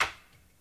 TC Clap Perc 07.wav